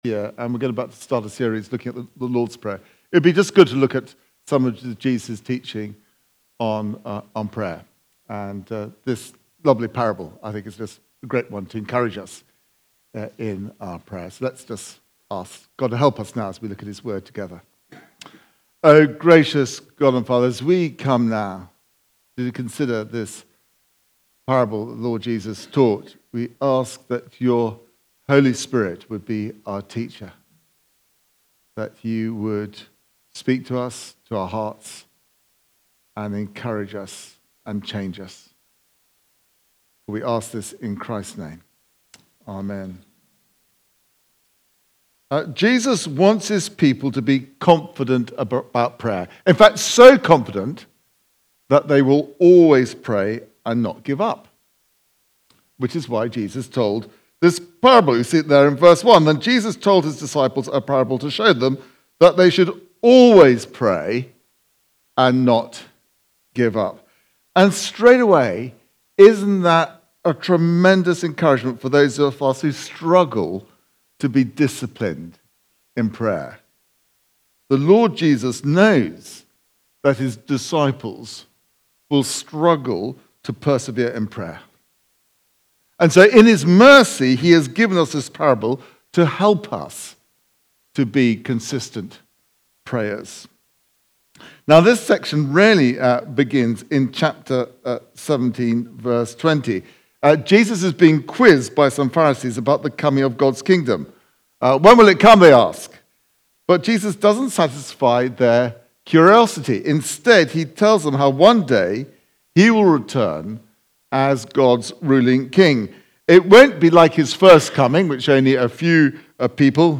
Preaching
Recorded at Woodstock Road Baptist Church on 25 May 2025.